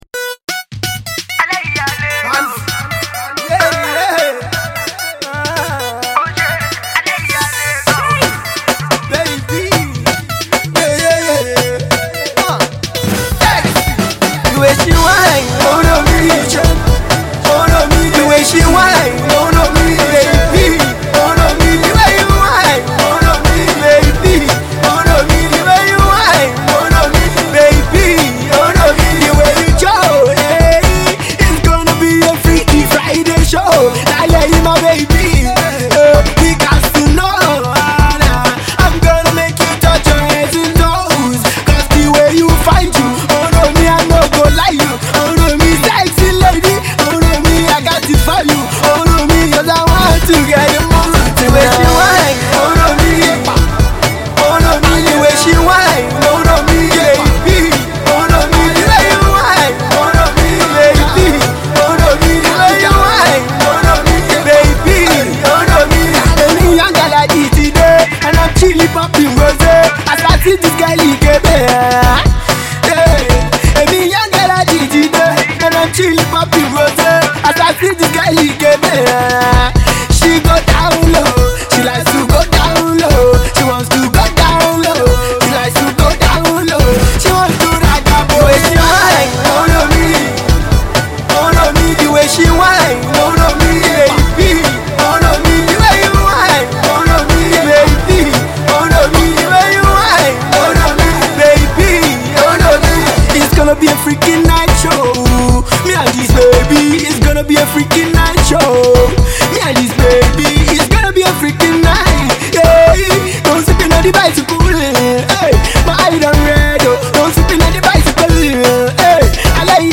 does not disappoint on this Afro-Dance joint